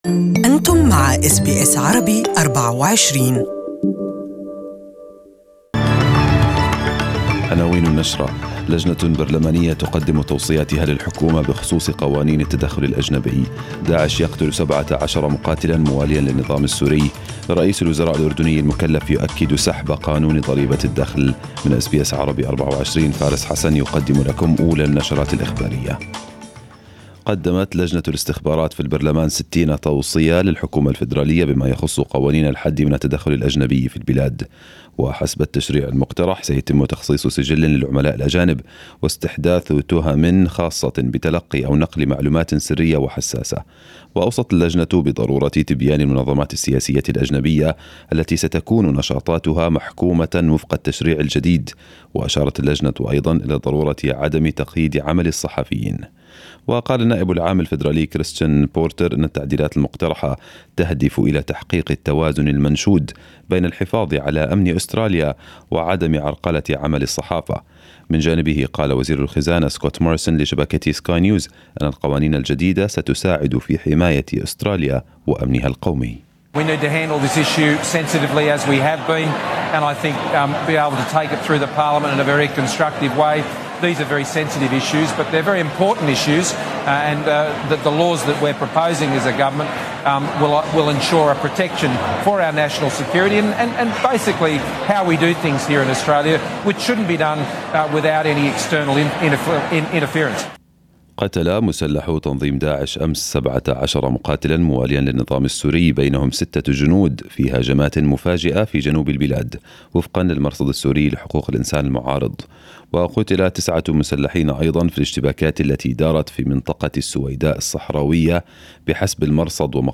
Arabic News Bulletin 08/06/2018